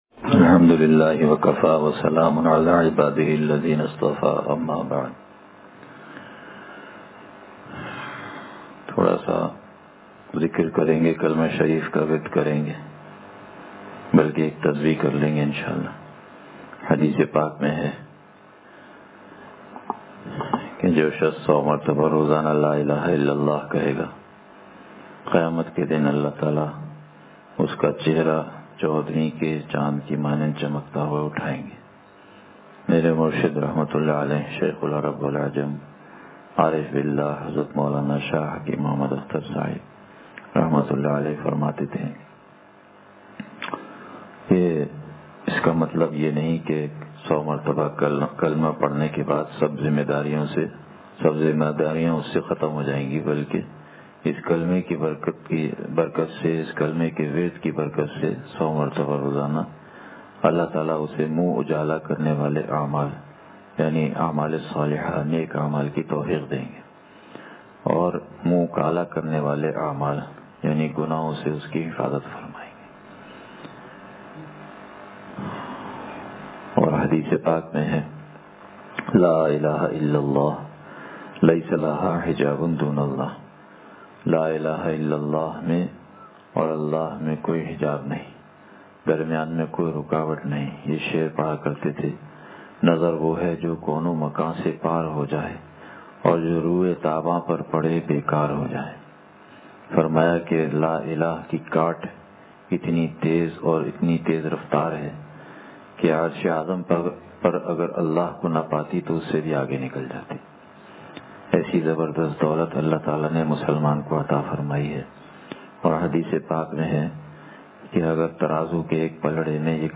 مجلسِ ذکر و چالیس احادیث